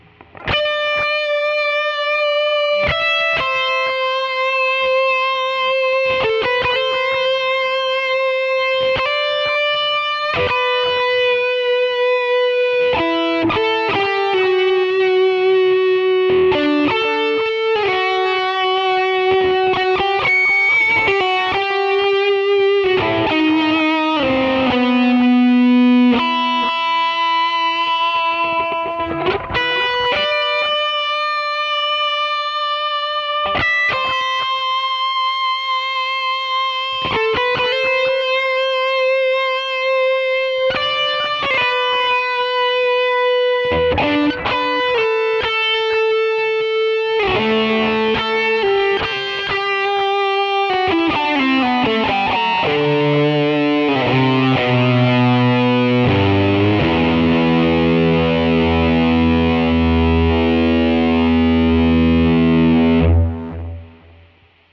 finally got around to testing out some real pedals with my podxt. got some really pleasant results with my Sobbat DriveBreaker I... basically a souped-up handmade japanese tubescreamer... same chip, but a lot more distortion, as well as better clean boost capabilities and separate bass and treble controls.
for this sound, i used the highway 100 amp, and recorded with three separate cabs... the 4x12 v30, 4x12 green 25, and 1x12 blackface. ibanez 540, bridge humbucker. i'll post settings later, but they won't do you much good without the pedal... pedal settings are:
LeadDrivebreaker (1x12 Blackface) 1.22 MB (1:04)   as above, with 1x12 blackface cab